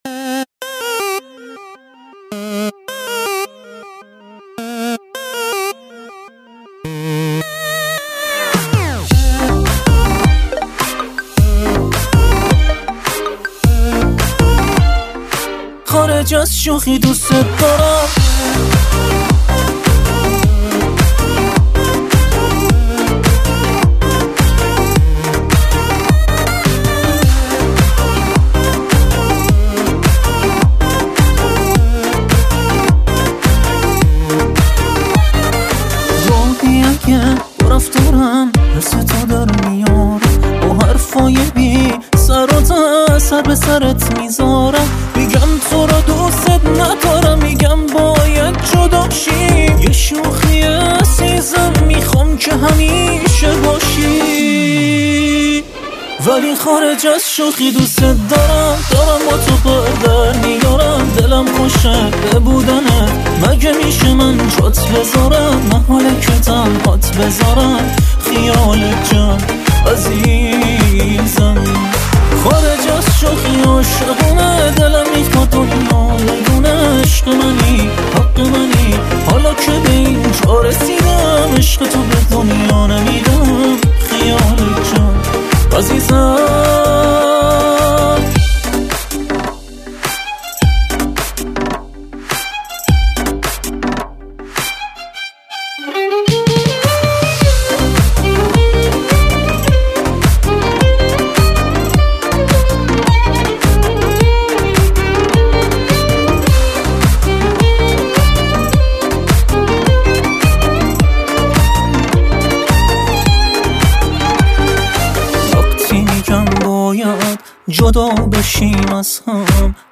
دانلود آهنگ شاد , آهنگ شاد ایرانی